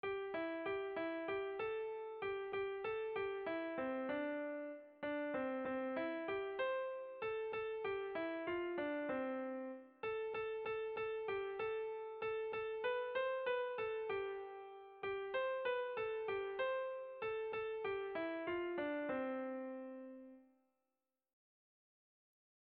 Gabonetakoa
Zortziko txikia (hg) / Lau puntuko txikia (ip)
ABDB2